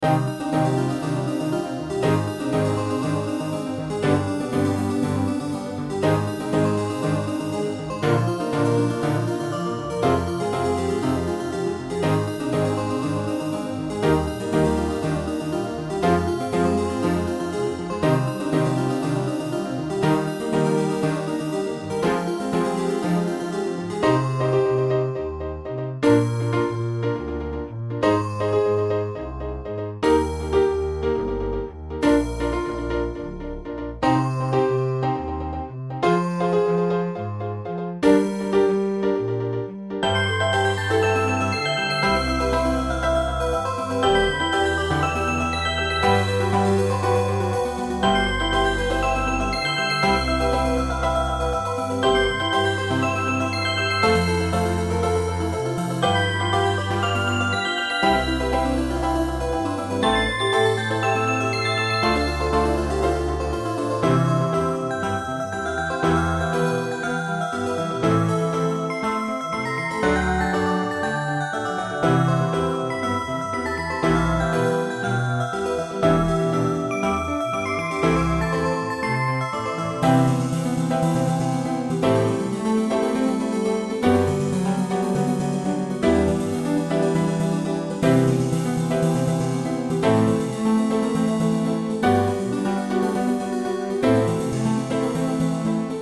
Fx5(ブライトネス)、ピアノ、コントラバス